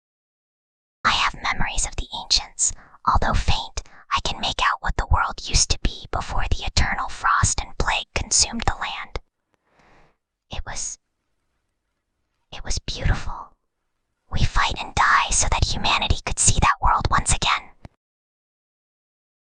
Whispering_Girl_32.mp3